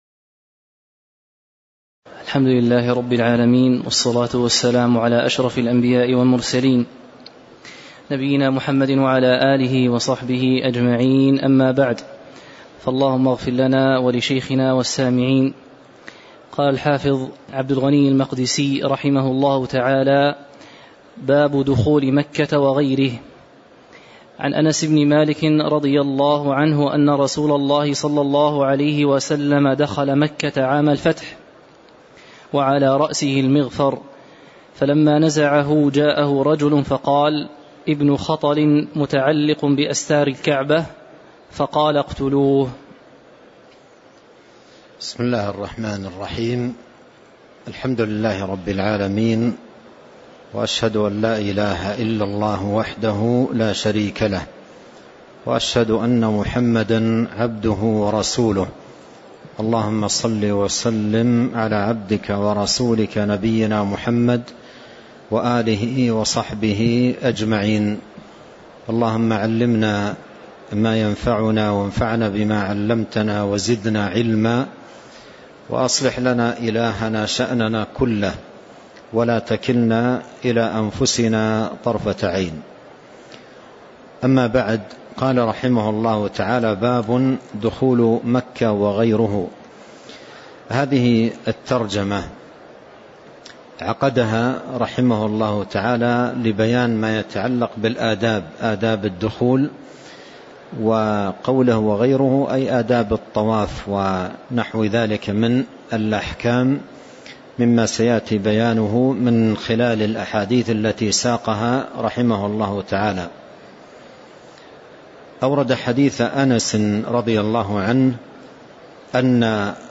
تاريخ النشر ٣ ذو الحجة ١٤٤٣ هـ المكان: المسجد النبوي الشيخ